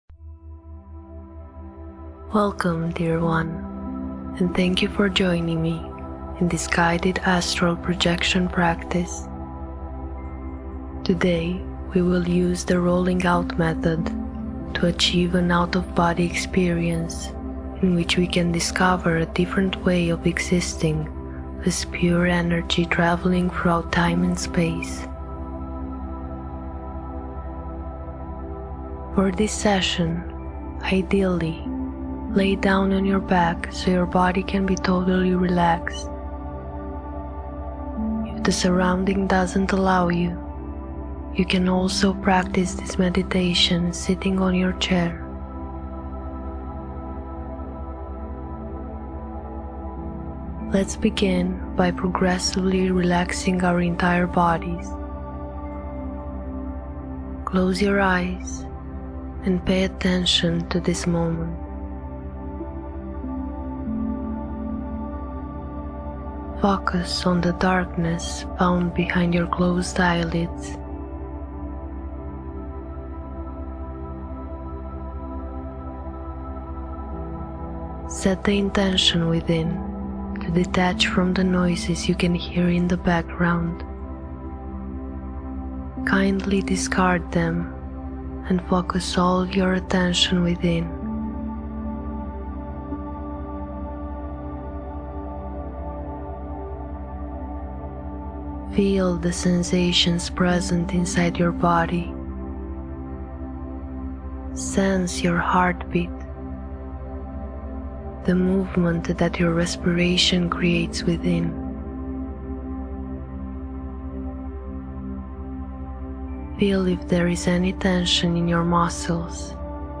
Guided Astral Projection Meditation
OBEAstralProjectionGuidedAstralProjectionMeditationHypnosisEN.mp3